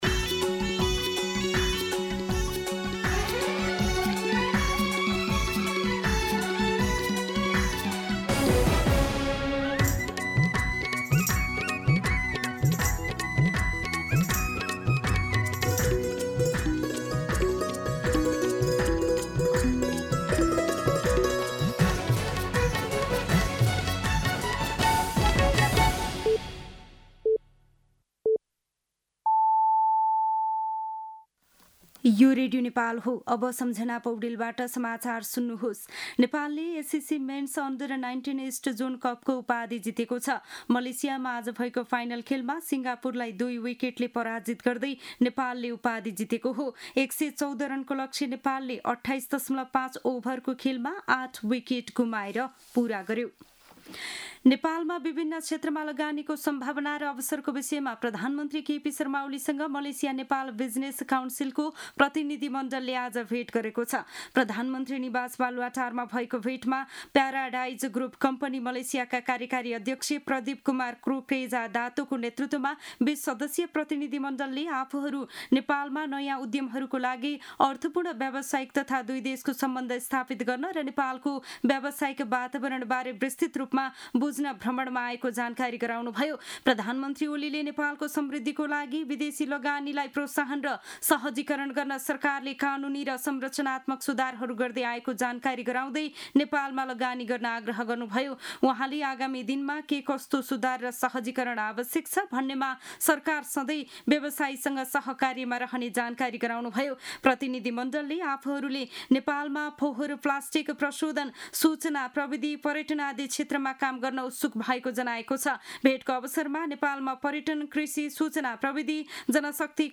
दिउँसो १ बजेको नेपाली समाचार : २१ असार , २०८२
1pm-News-21.mp3